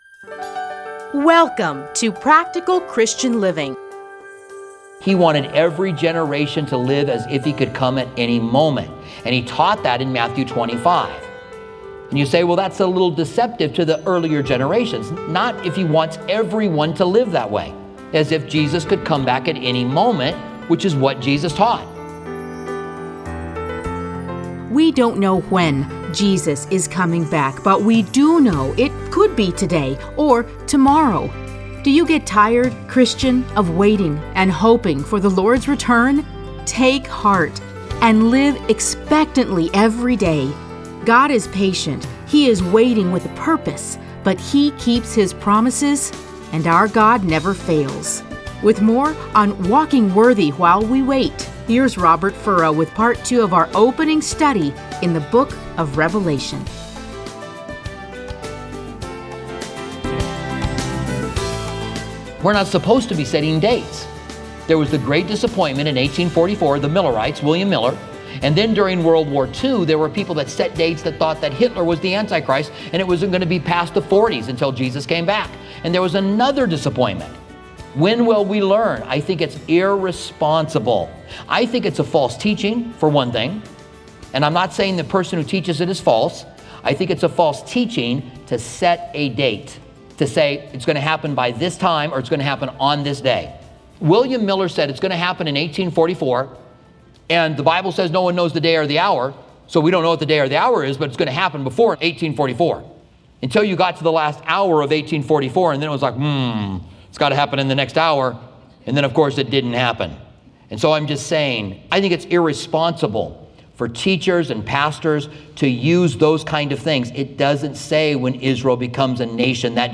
Listen to a teaching from Revelation 1:1-3.